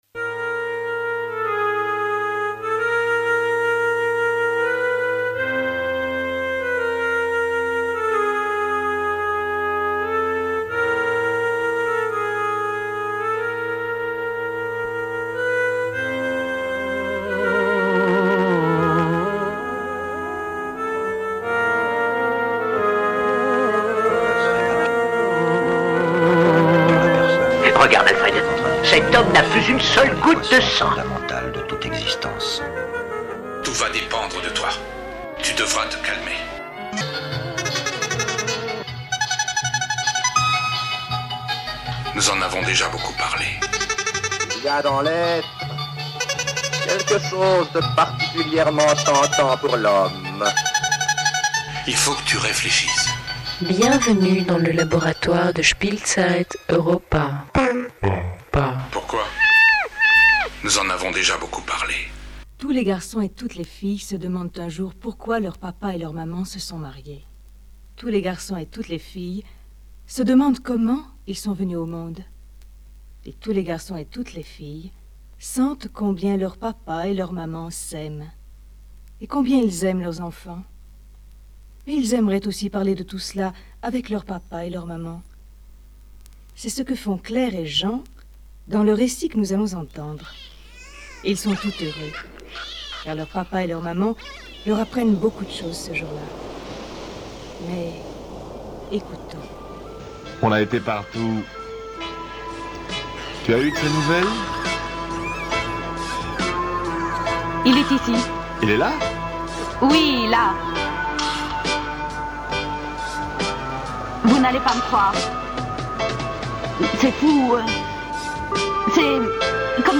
Blog,Mix,Mp3,collage sonore